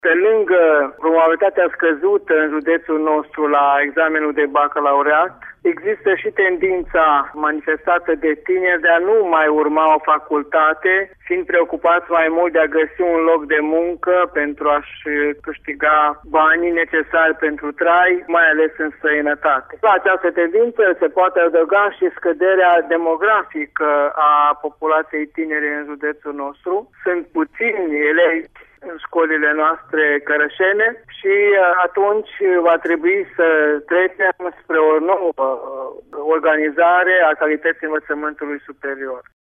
Subiectul a fost dezbătut pe larg astăzi, în emisiunea „Ascultă cum te ascult”, al cărei invitat a fost rectorul Doina Frunzăverde.